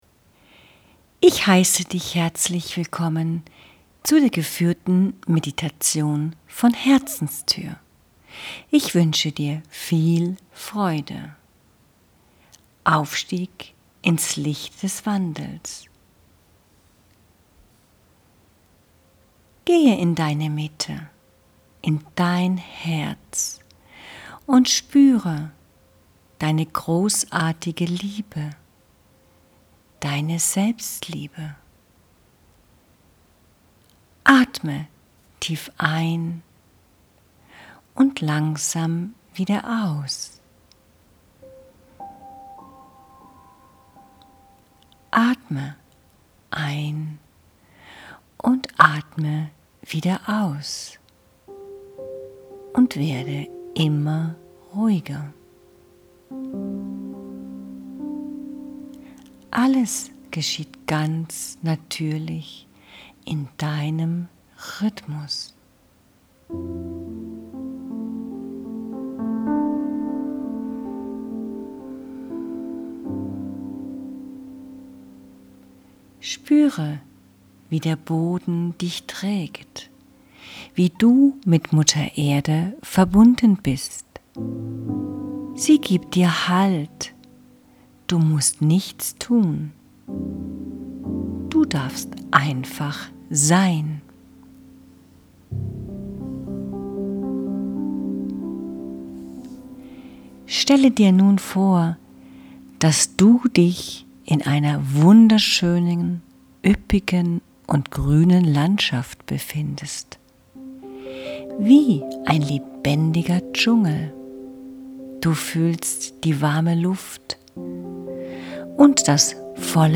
Lichtvolle Meditation für Deinen inneren Aufstieg - Deinen Wandel
Connected to the energy of this meditation, he allowed the music to arise in a way that carries heart and gentle transformation.